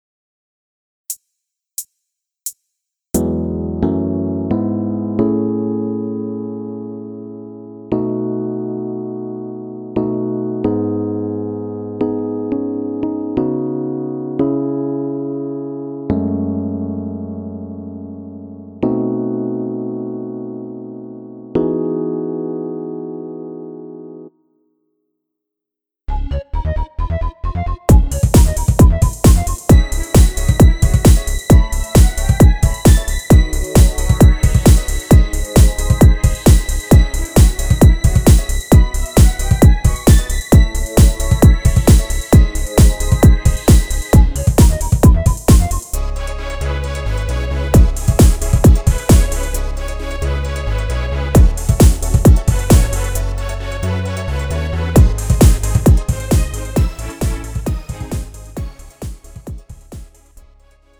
장르 가요 구분 Lite MR
Lite MR은 저렴한 가격에 간단한 연습이나 취미용으로 활용할 수 있는 가벼운 반주입니다.